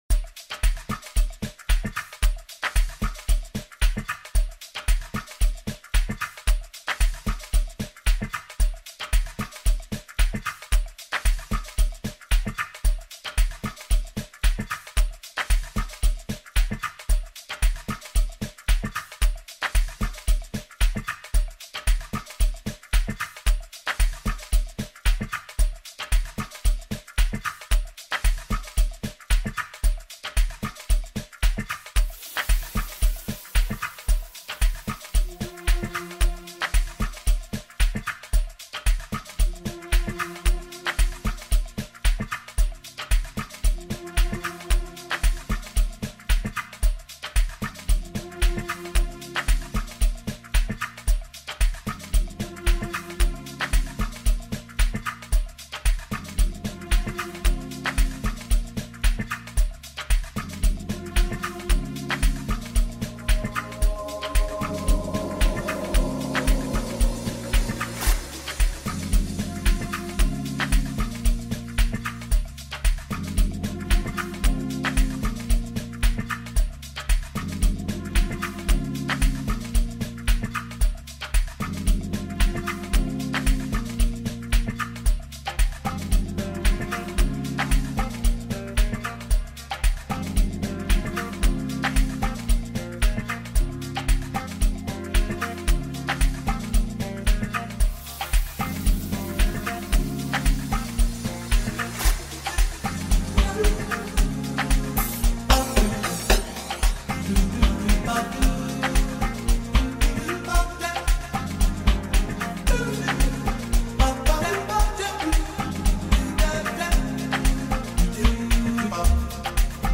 With its infectious rhythm and catchy melodies
It’s a perfect blend of catchy hooks, energetic beats